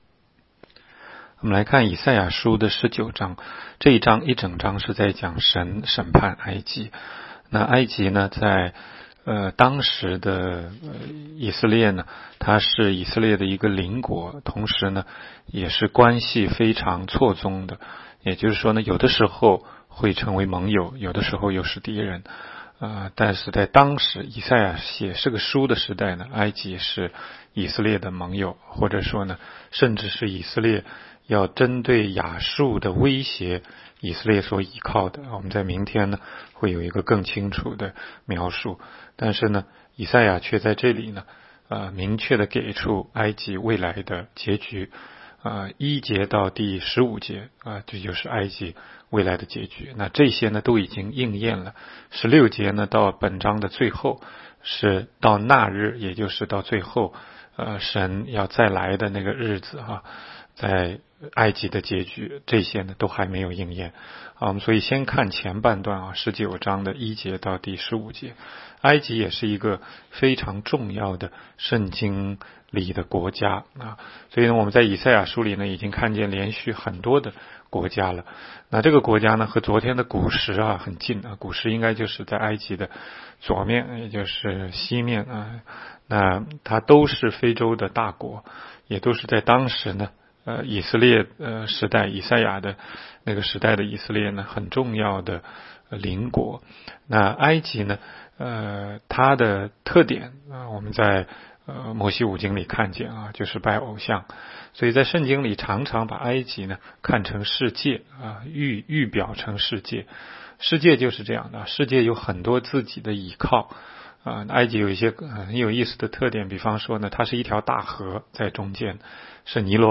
16街讲道录音 - 每日读经 -《 以赛亚书》19章